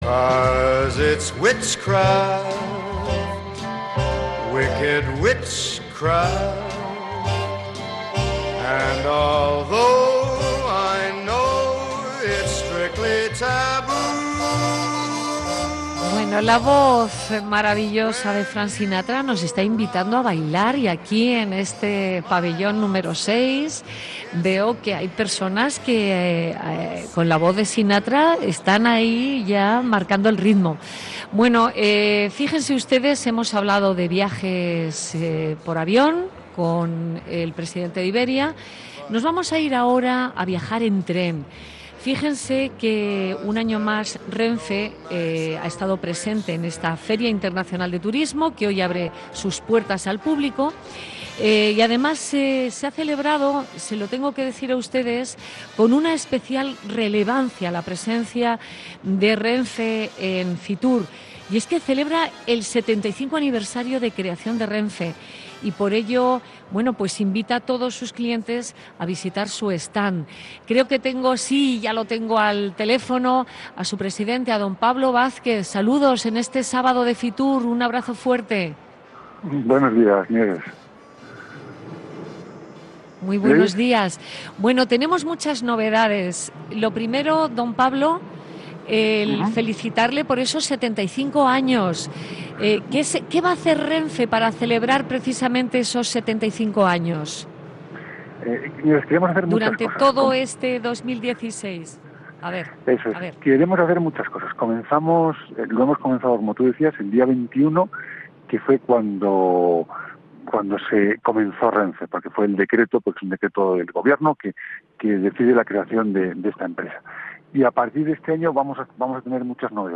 2016 va a ser un año muy importante para Renfe ya que celebrará su 75 aniversario. En Vivir viajar tuvimos la ocasión de conversar con Pablo Vázquez, su presidente.